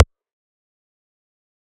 EDM Kick 39.wav